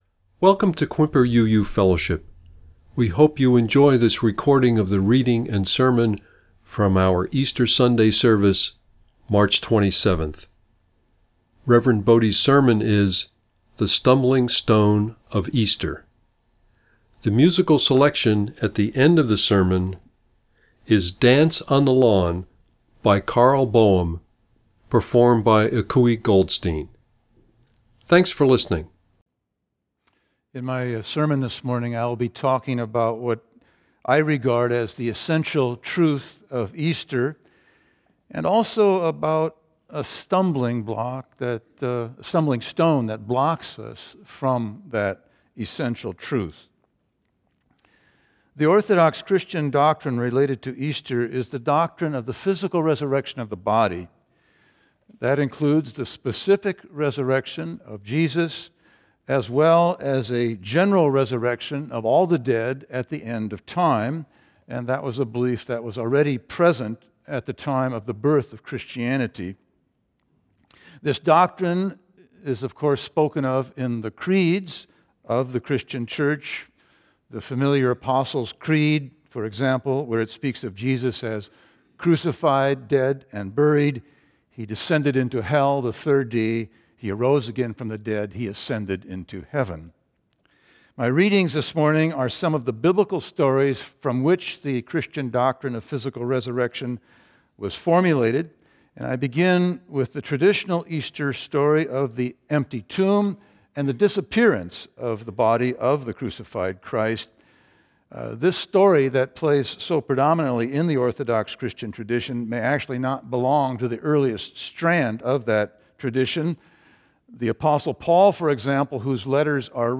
This sermon will explore a different route to the truth of Easter. Click here to hear the reading and sermon.